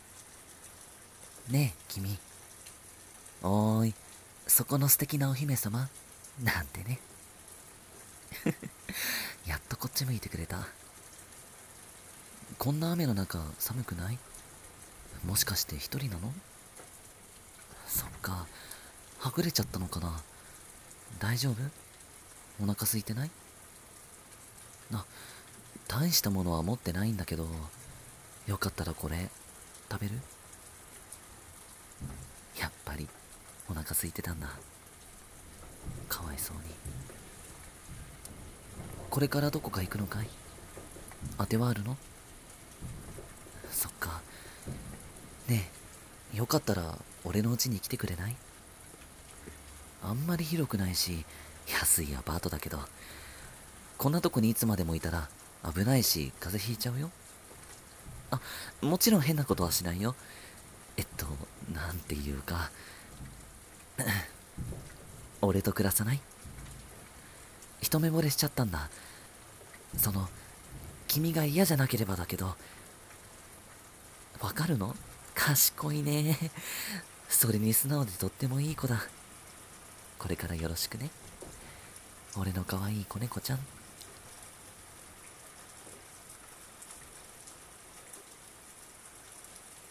【一人声劇】子猫ちゃん